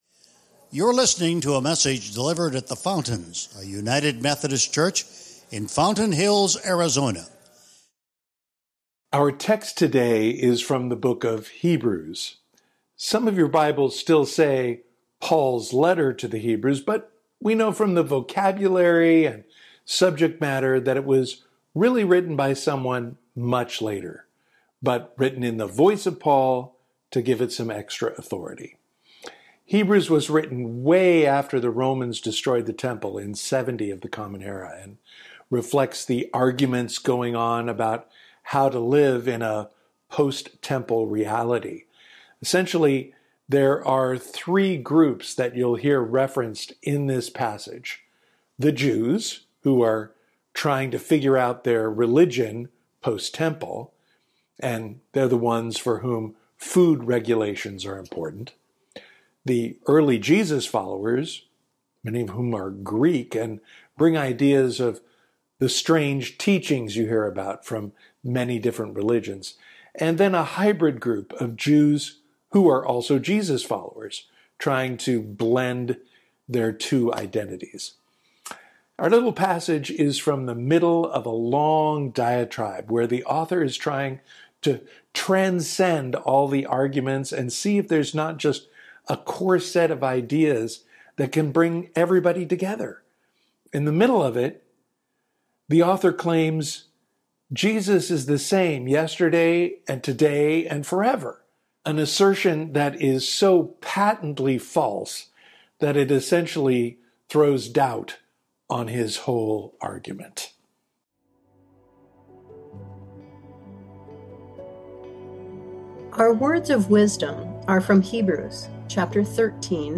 Sermon Archives | The Fountains, a United Methodist Church |